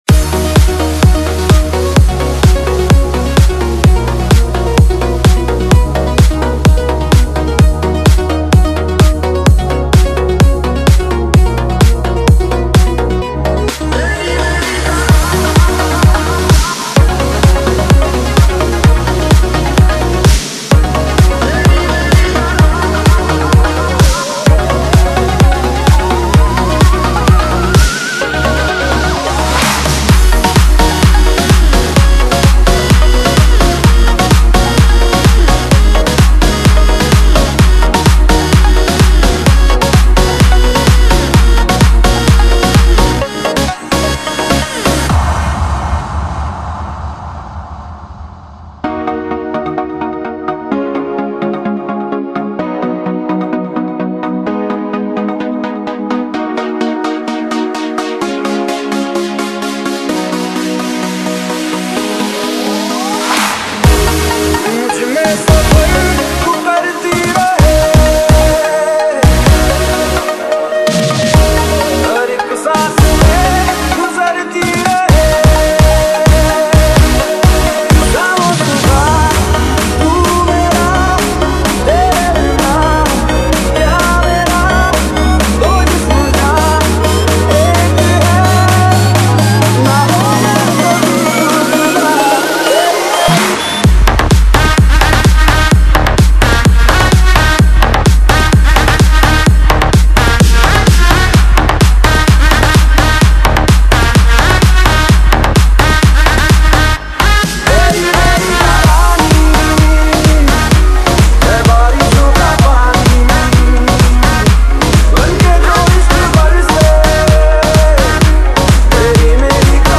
DJ Remix Mp3 Songs > Single Mixes